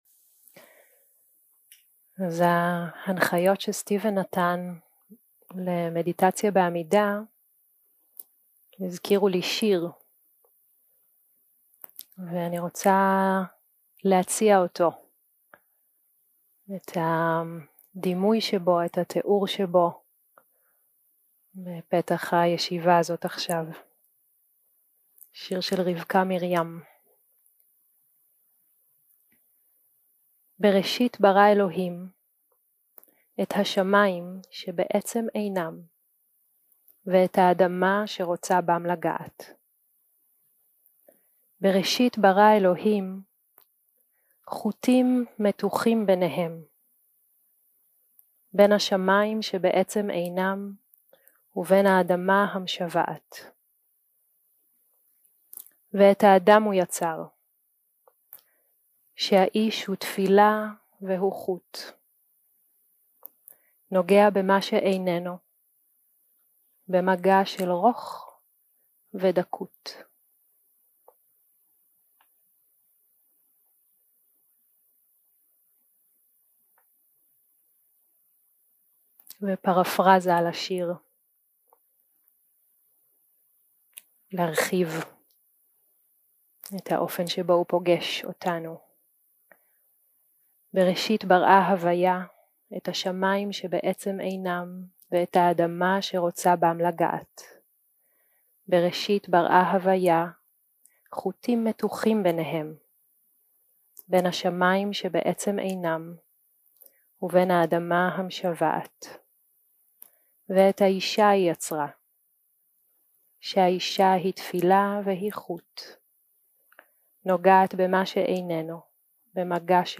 יום 3 - הקלטה 6 - צהרים - מדיטציה מונחית
סוג ההקלטה: מדיטציה מונחית